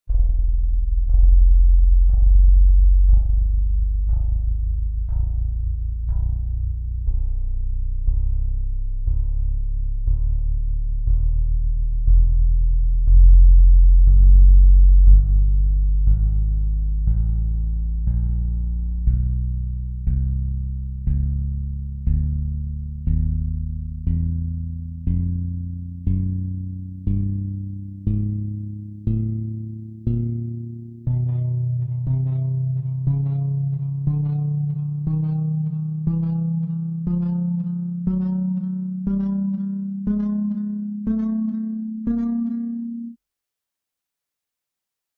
Вот такая басс гитарка :gg:
Дык это просто демонстрация басс гитарки а не трек :lol:
Bass.mp3